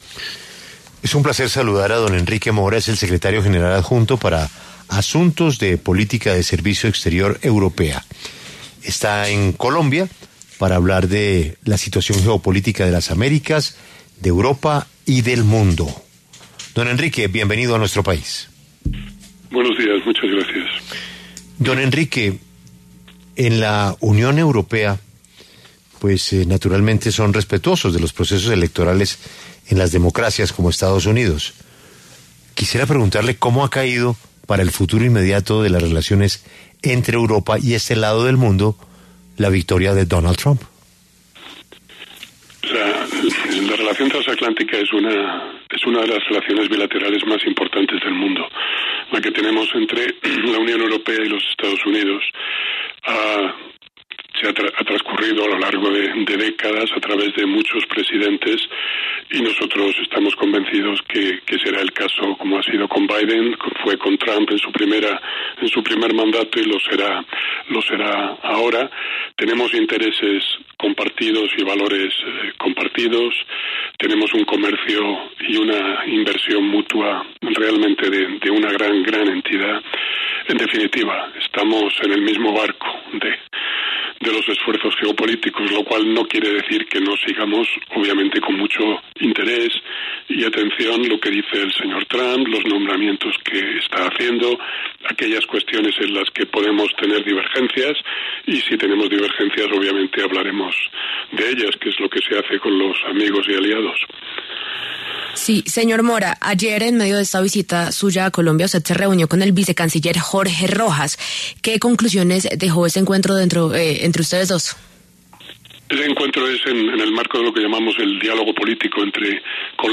En diálogo con La W, el secretario general mencionó que la victoria de Donald Trump cayó muy bien para la relación transatlántica entre la Unión Europea y los Estados Unidos, que según dijo “es una de las relaciones bilaterales más importantes del mundo”, debido a que al paso del tiempo ha pasado por muchos presidentes y les fue muy bien con Trump en su primer mandato (2017–2021).